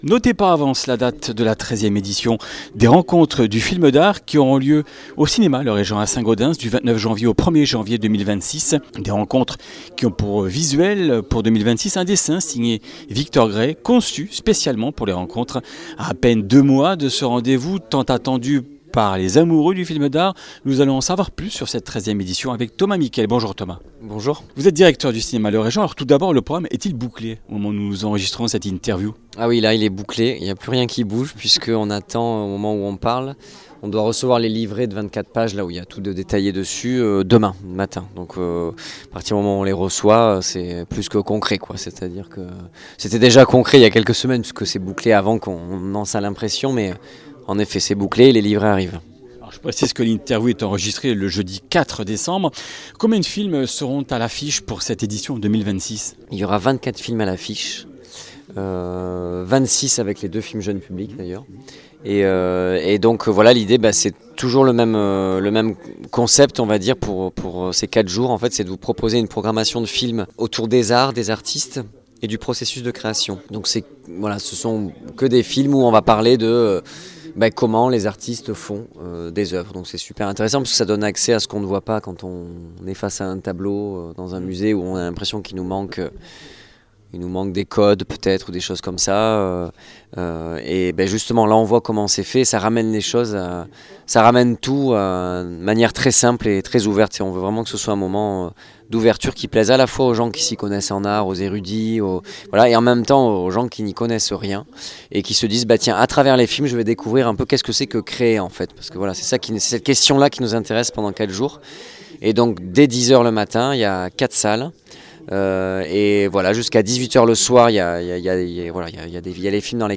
Comminges Interviews du 10 déc.